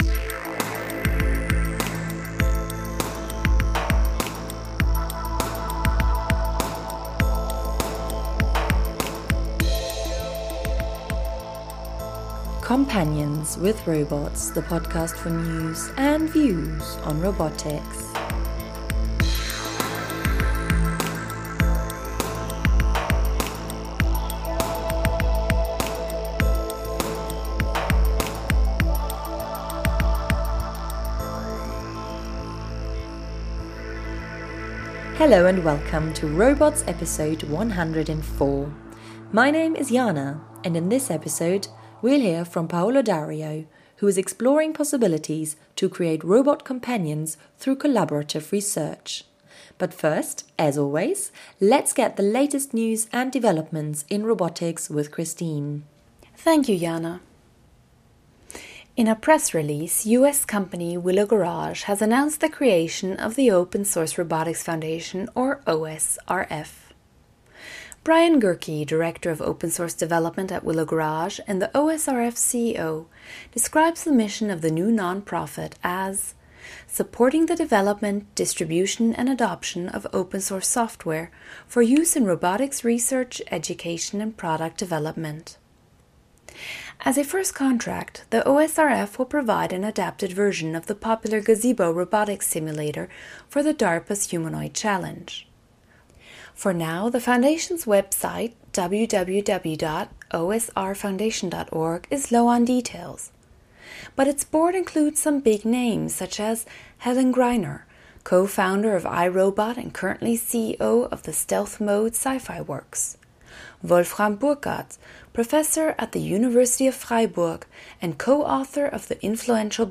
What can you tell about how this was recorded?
In this interview recorded at the Robotdalen Robotics Innovation Challenge